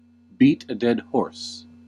Ääntäminen
US : IPA : [biːt ə dɛd ˈhɔɹs]